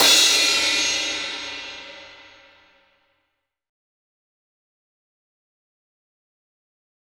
Jfx Crash.wav